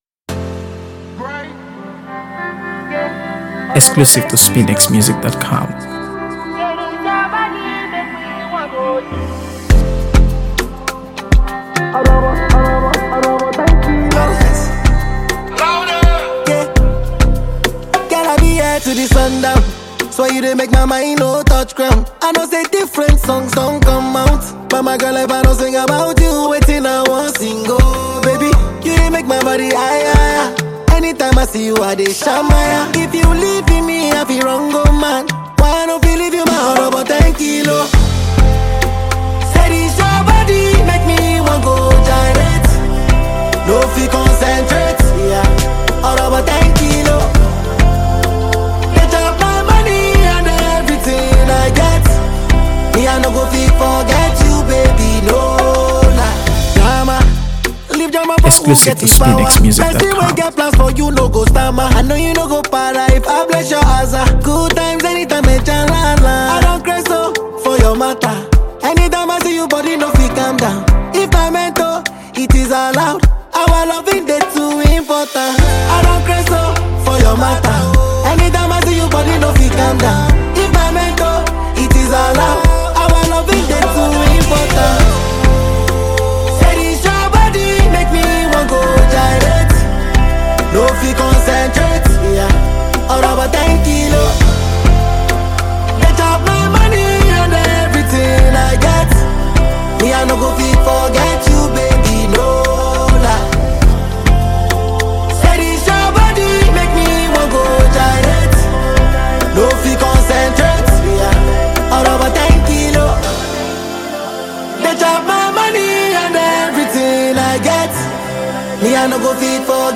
AfroBeats | AfroBeats songs
high-energy new single
dancefloor-ready production